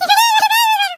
squeak_kill_vo_01.ogg